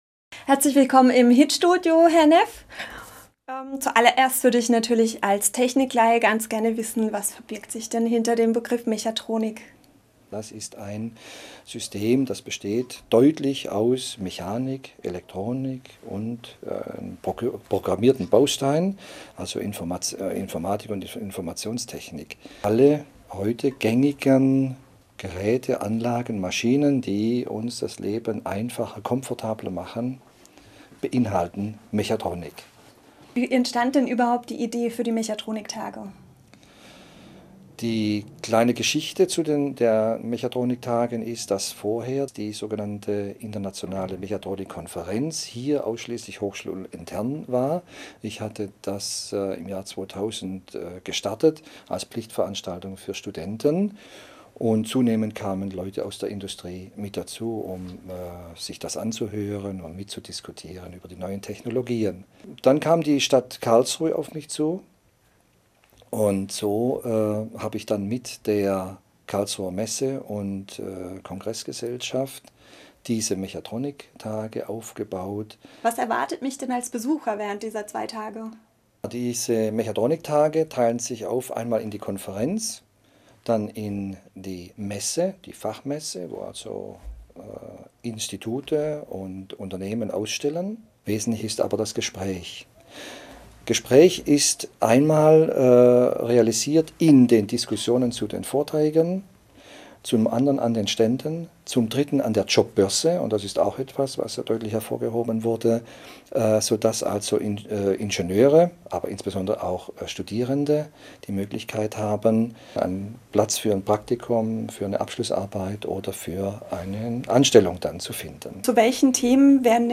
Interview_Mechatronik-Tage